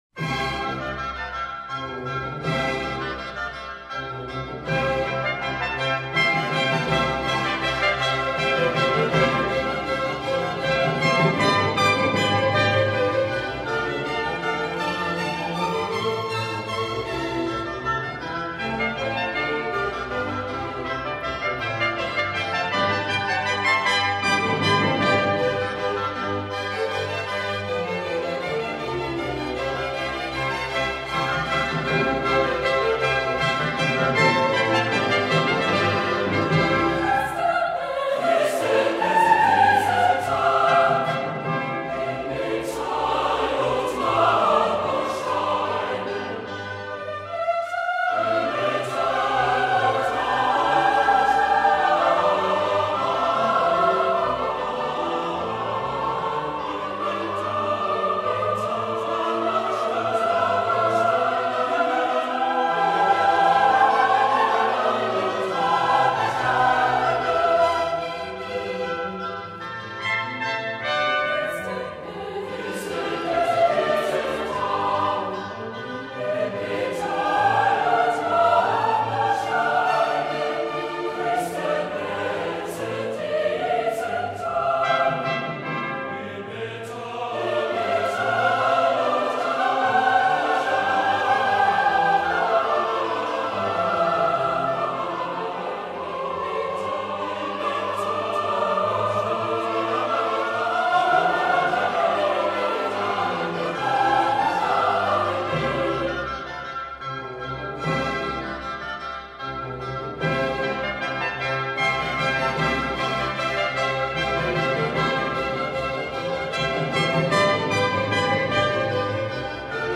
The very best Choruses and Chorales from Bach's Cantatas.